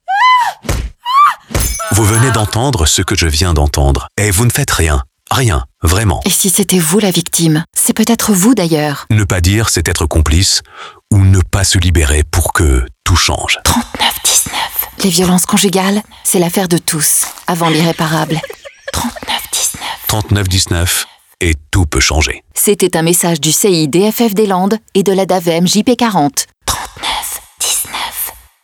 Le spot radio, conçu par la station, est diffusé pendant 52 semaines sur les 7 fréquences de FGL. L’objectif de cette diffusion est de contribuer à éveiller les consciences et d’encourager les personnes concernées à réagir face aux violences intrafamiliales.